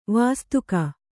♪ vāstuka